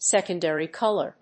アクセントsécondary cólor